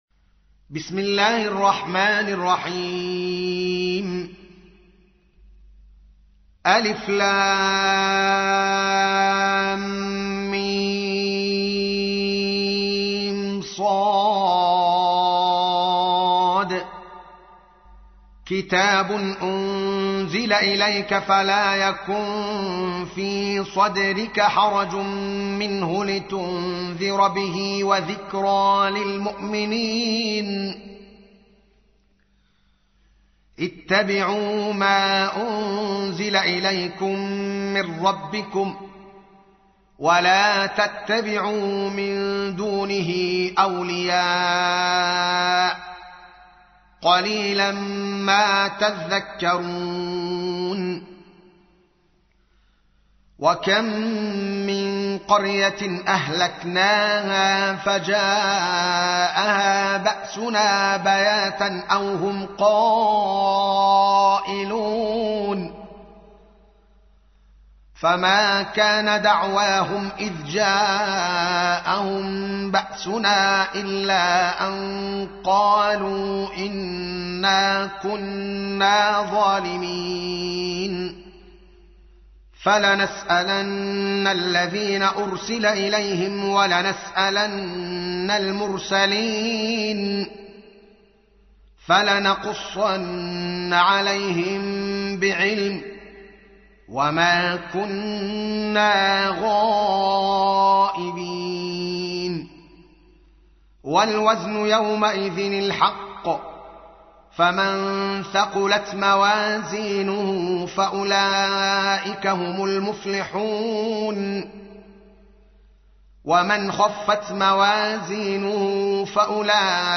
7. سورة الأعراف / القارئ